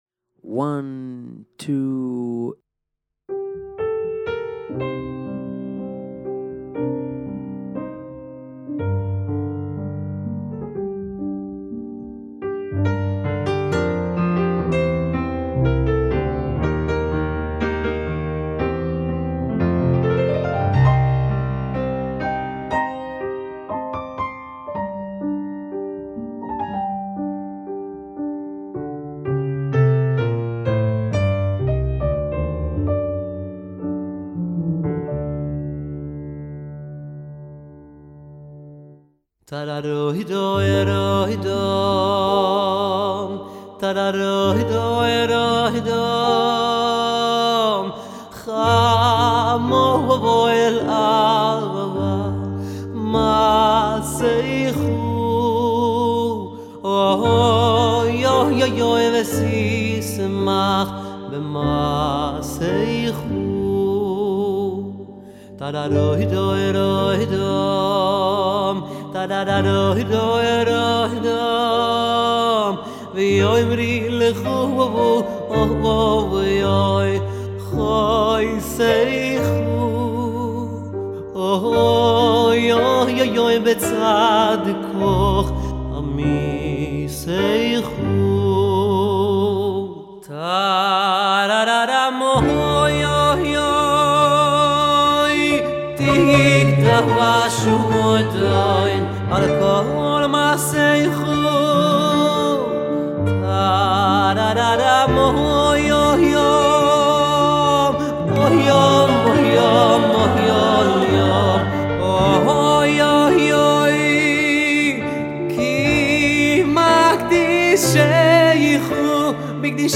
שירים חסידיים